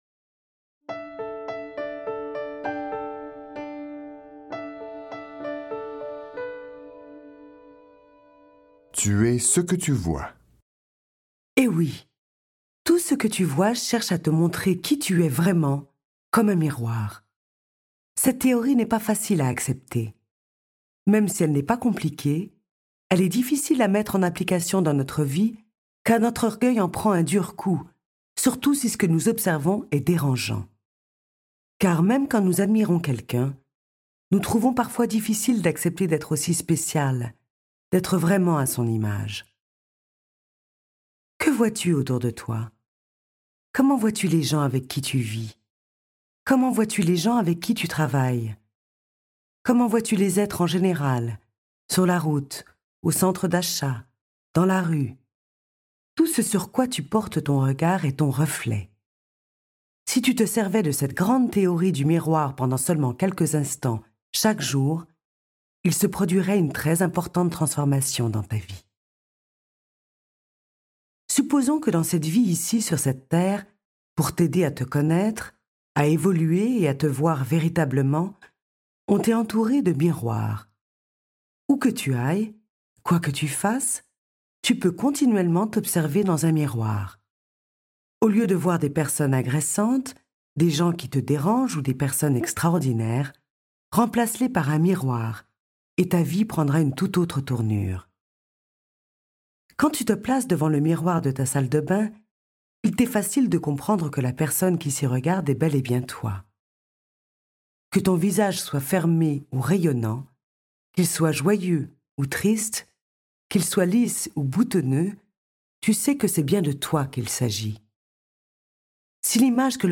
L'auditeur désireux de découvrir QUI IL EST puisera des trésors d'information dans ce livre audio.